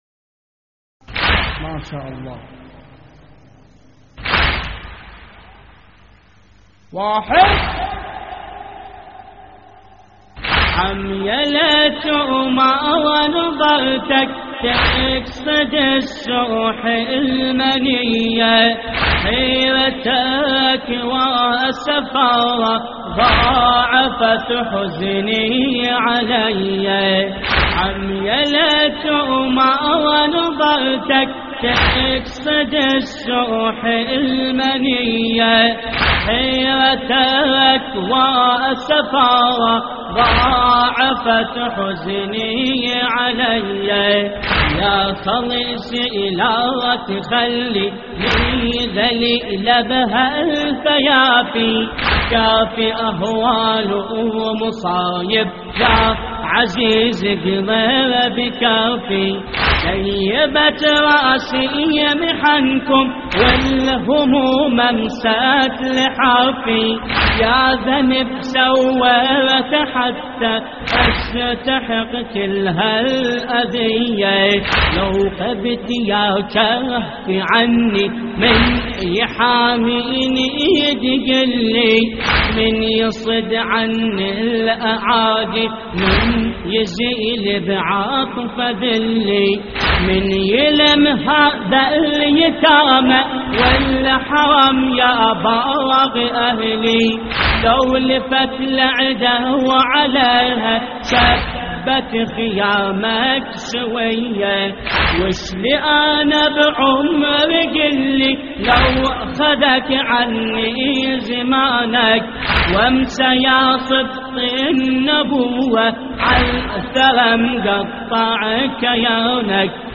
عمية ليت وما نظرتك تقصد لسوح المنية (آهات) - استديو